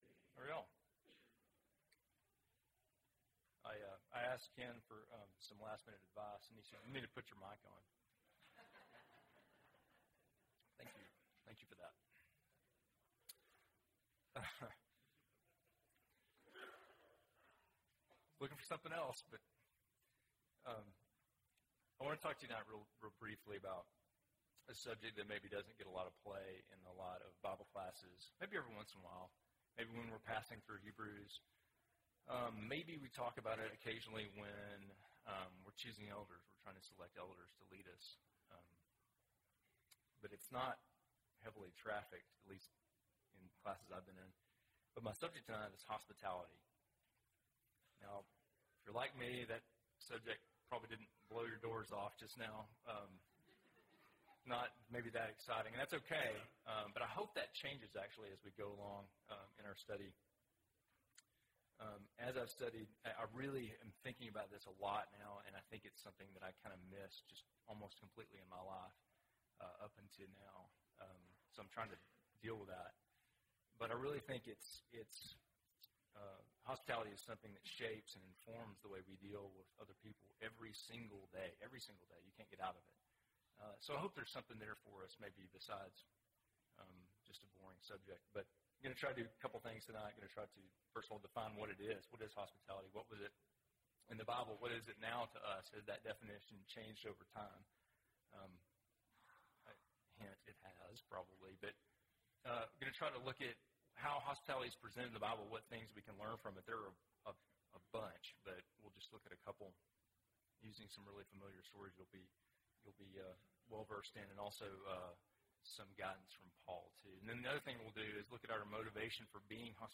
Sunday PM Sermon